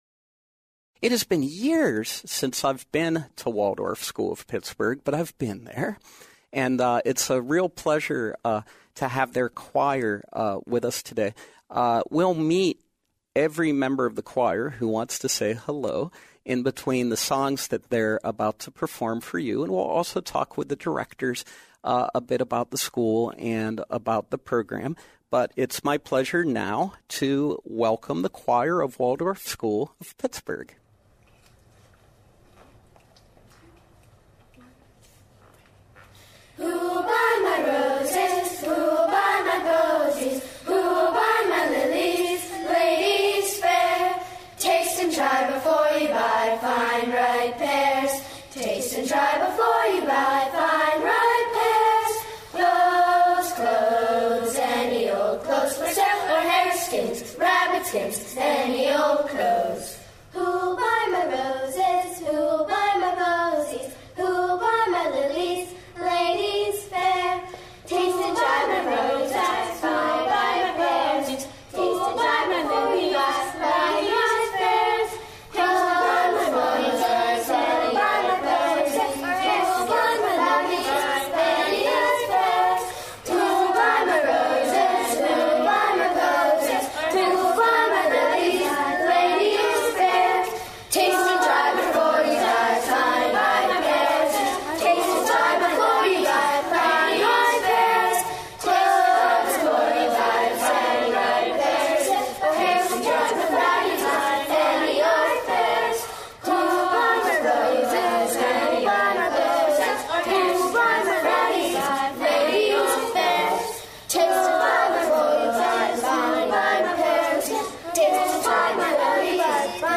The Waldorf School of Pittsburgh Choir consists of all 22 children in the 4th and 5th grades.
In this first year of their conception, we are proud to host them, live on SLB.